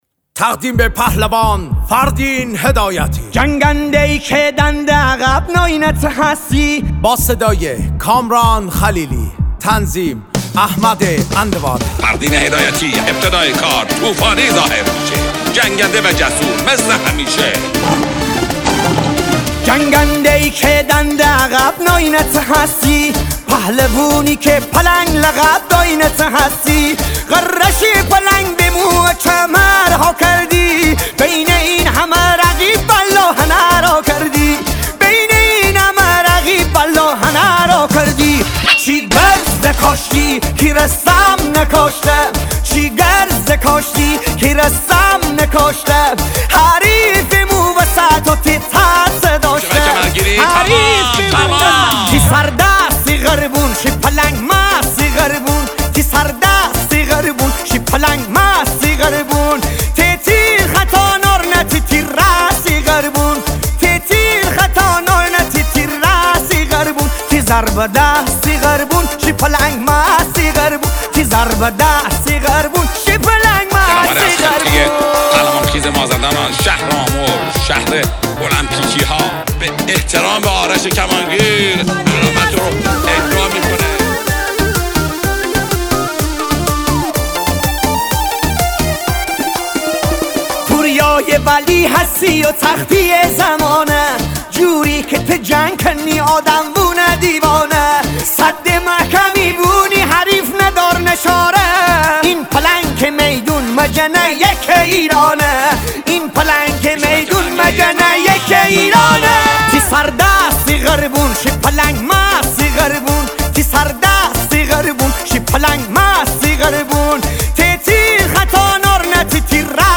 شاد
با سبک شاد مازندرانی